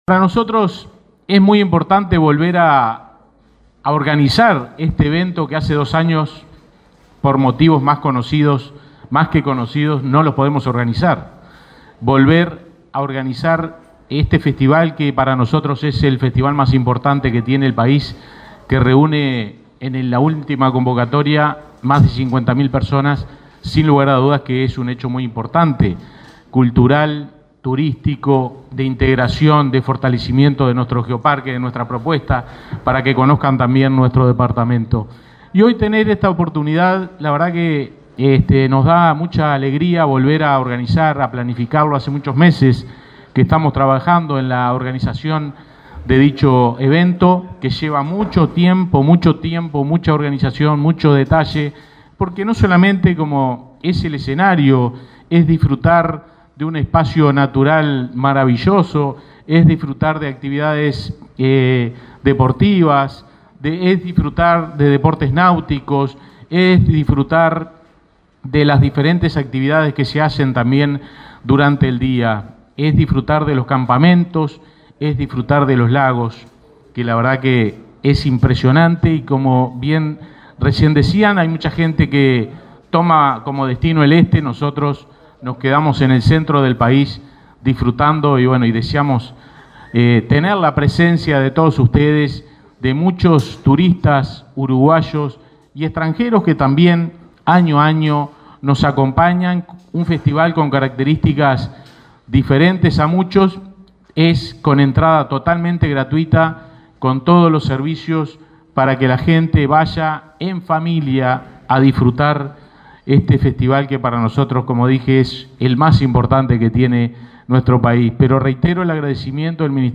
Palabra de autoridades en el Ministerio de Turismo